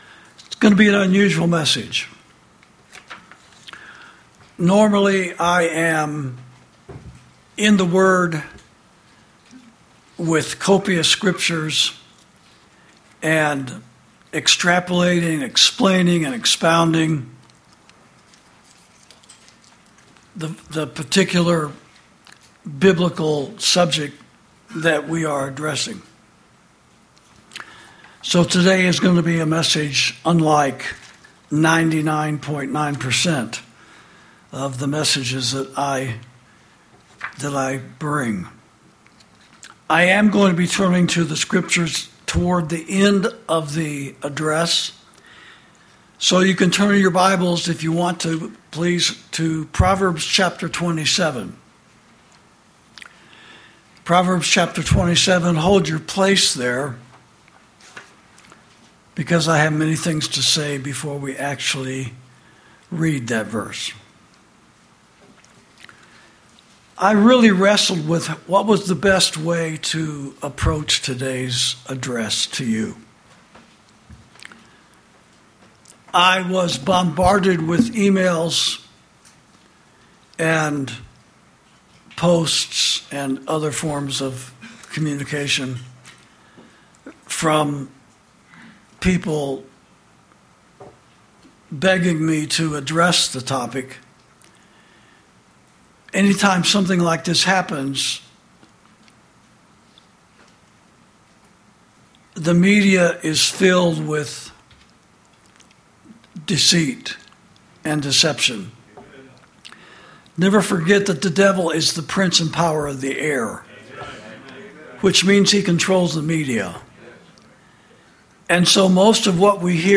Sermons > Observations, Questions And Consequences Regarding The Assassination Of Charlie Kirk
This message was preached by Pastor Chuck Baldwin on Sunday, September 14, 2025, during the service at Liberty Fellowship.